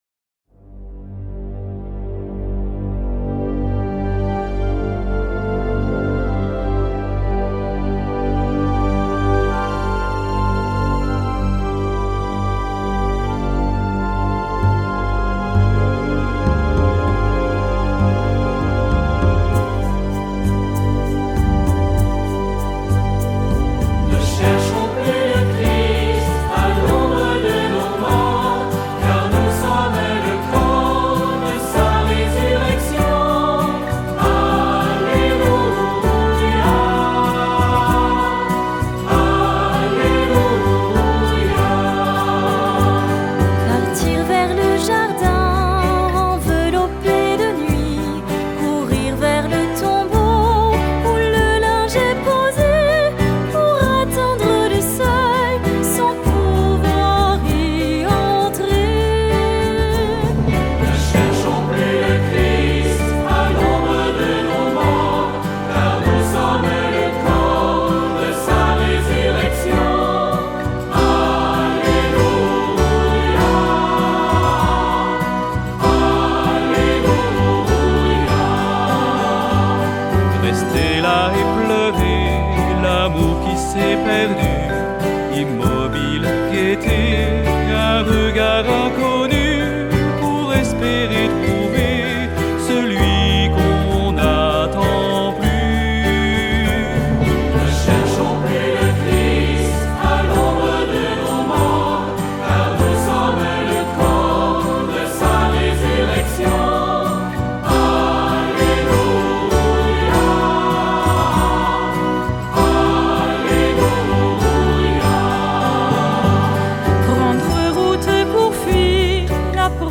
♫ Chant : Ne cherchons plus le Christ
(A. Cabantous / L. Boldrini)